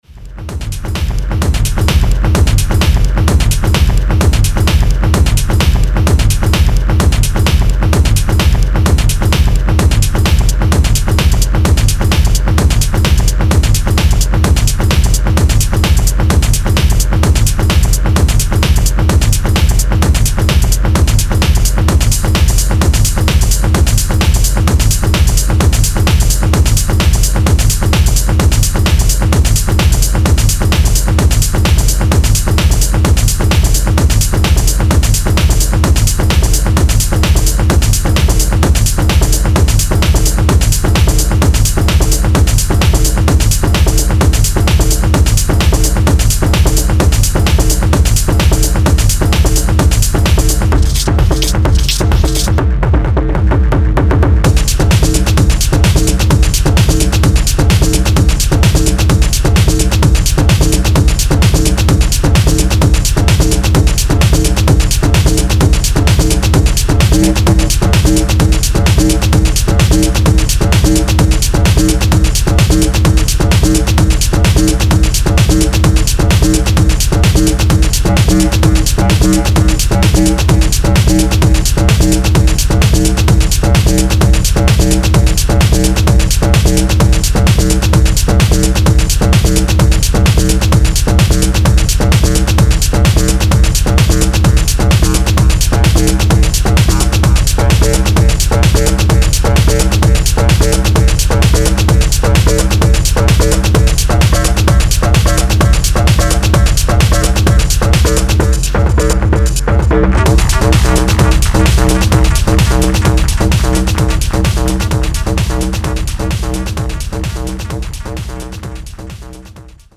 Dark Techno from Dublin on blood red vinyl
Style: Techno / Dark Techno